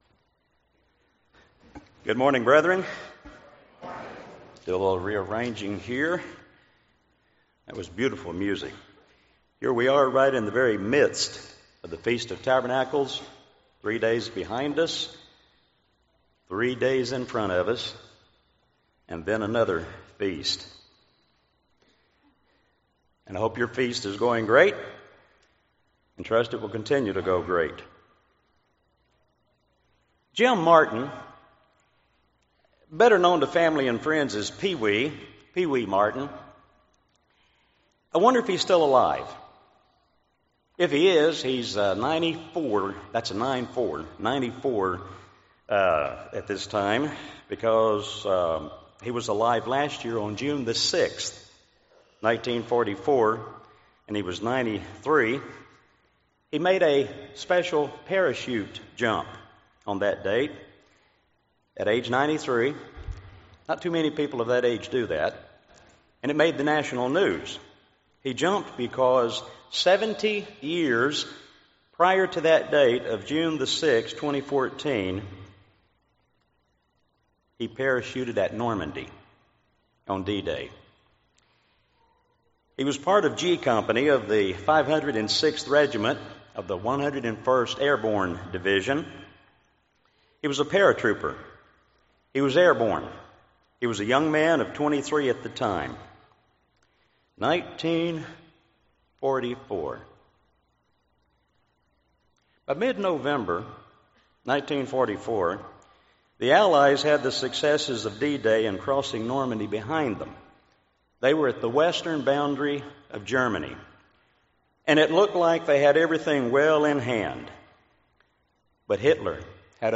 United Church of God Feast of Tabernacles services: Panama City Beach,...
Sermons